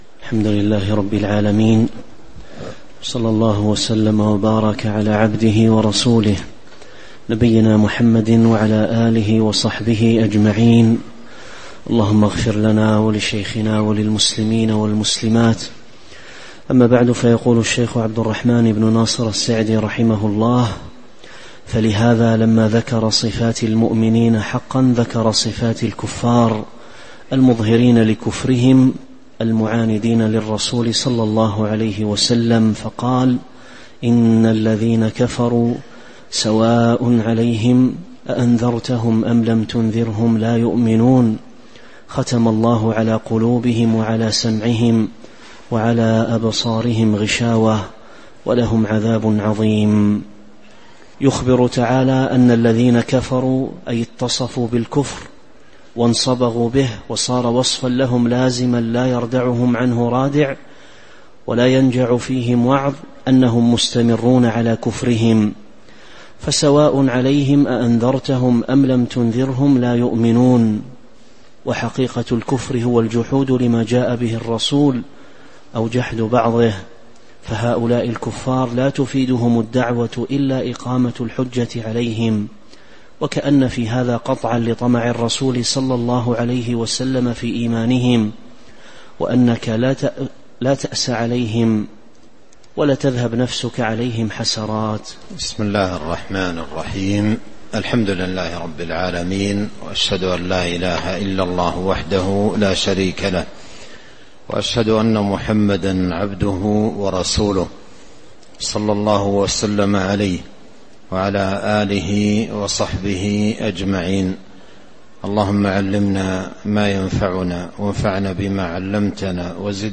تاريخ النشر ٢٤ ربيع الأول ١٤٤٦ هـ المكان: المسجد النبوي الشيخ